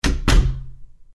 Звуки стука пальцев
Двери при закрывании